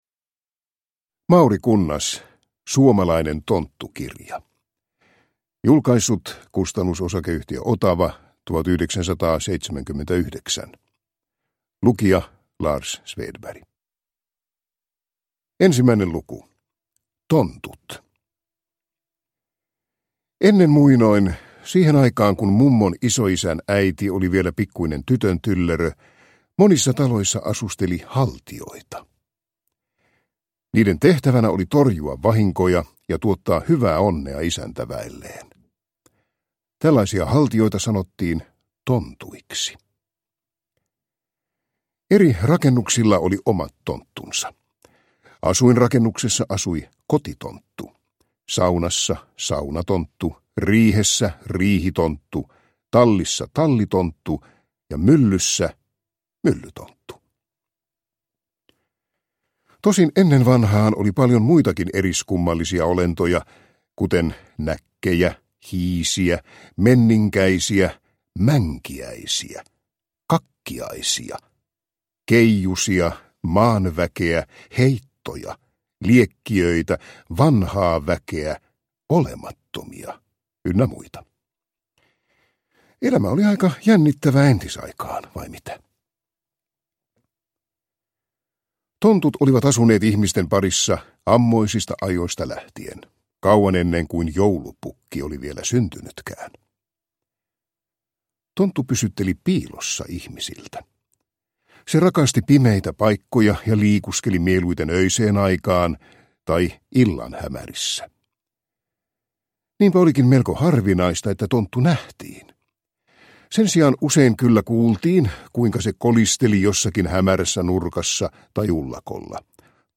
Suomalainen tonttukirja – Ljudbok – Laddas ner